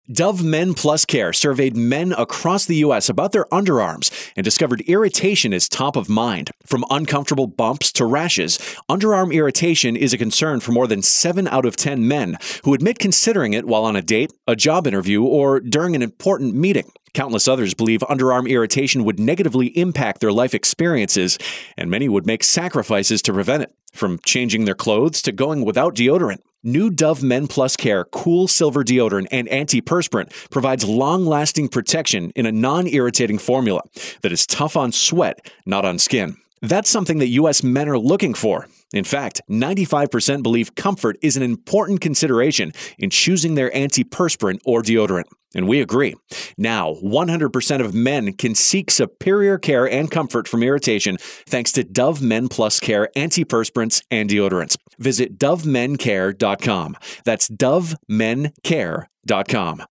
November 6, 2013Posted in: Audio News Release
VO: